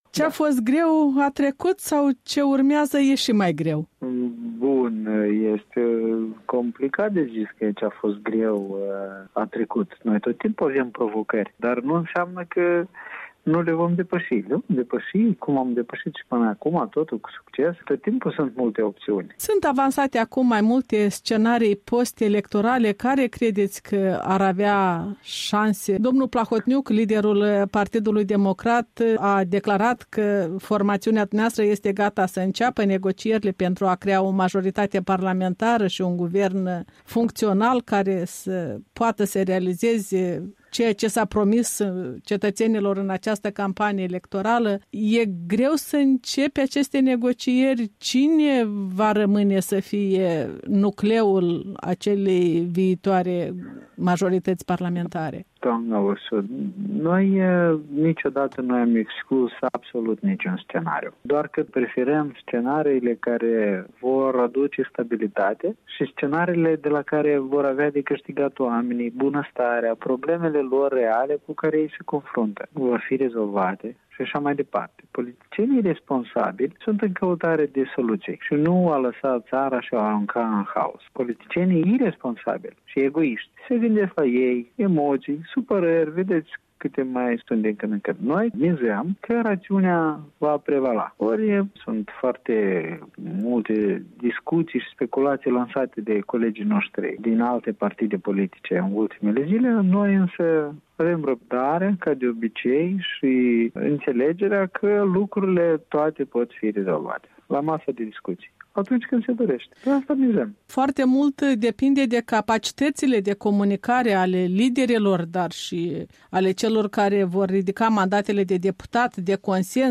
Un interviu cu vicepreședintele Partidului Democrat despre intențiile formațiunii sale după alegeri.